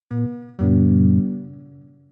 Звук полученного письма на электронный ящик, мелодии сообщений и уведомлений в mp3